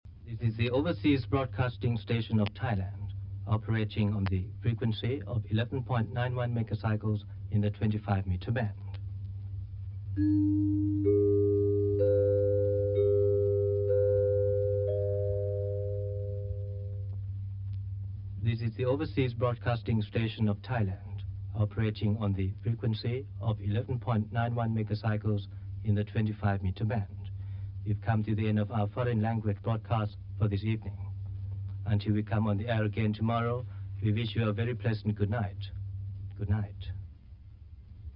Station ID Audio